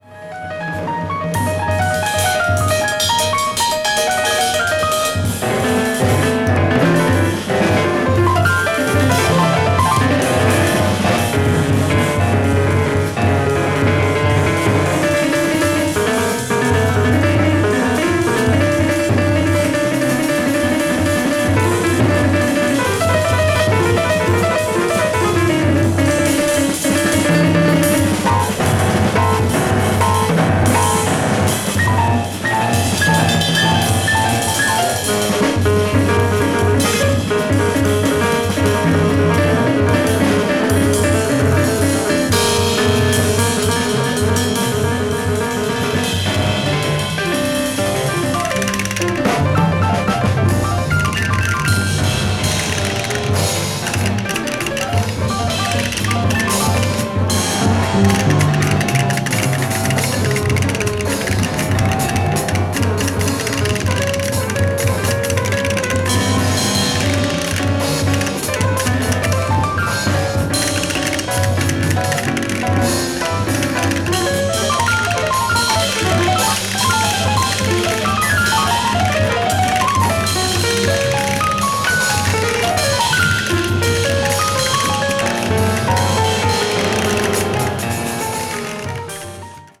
カオティックなサウンドスケープに飲み込まれていく強烈なインタープレイの応酬。
avant-jazz   contemporary jazz   free jazz   spiritual jazz